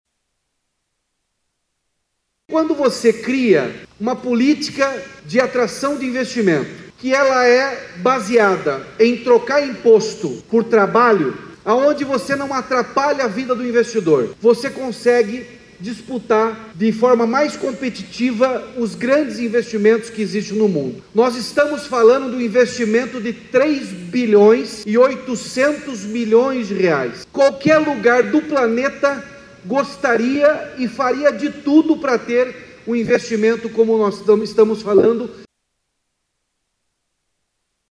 Também presente na assinatura do acordo, o governador do Paraná, Ratinho Junior (PSD), destacou a política de atração de investimentos no estado.